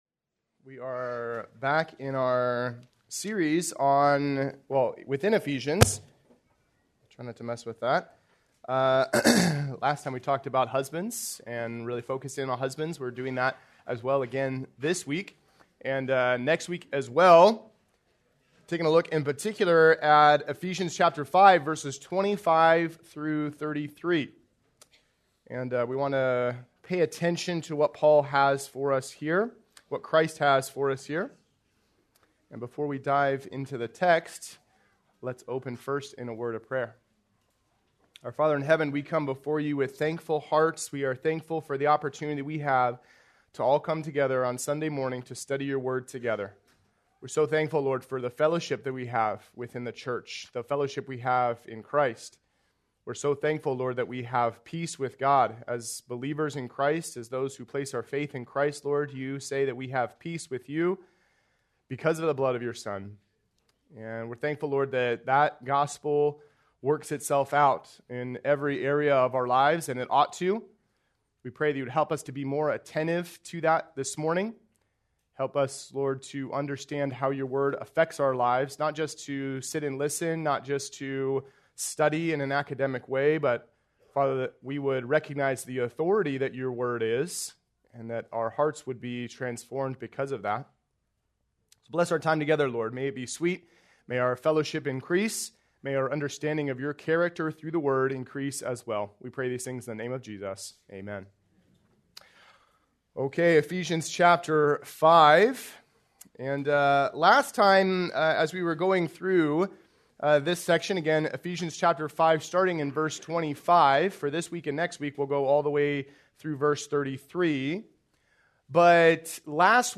March 22, 2026 - Sermon | Cornerstone | Grace Community Church